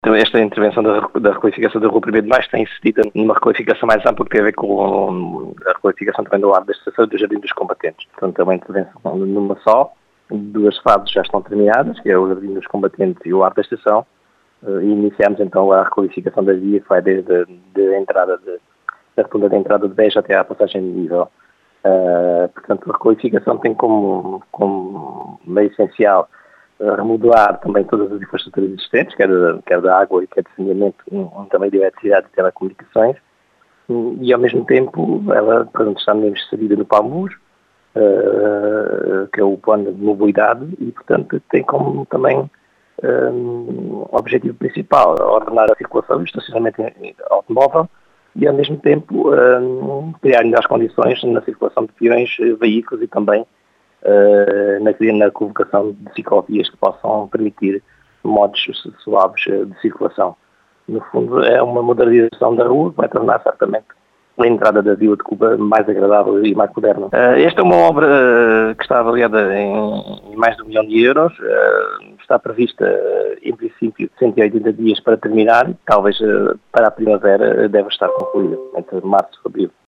Trata-se de uma obra orçada em um milhão de euros, com um prazo de execução de 180 dias, segundo explicou à Rádio Vidigueira, João Português, presidente da Câmara Municipal de Cuba, que realça a importância da requalificação para a ordenação do trânsito e circulação de peões.